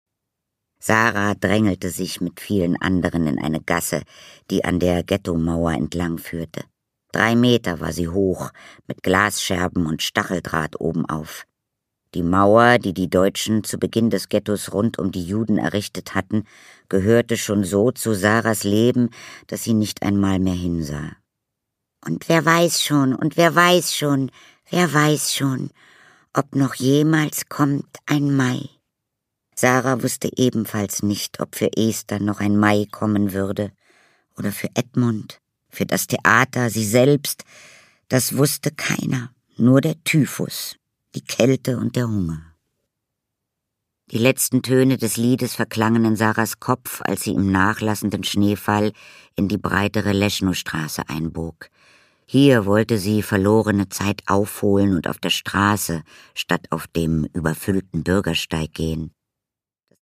Produkttyp: Hörbuch-Download
Gelesen von: Katharina Thalbach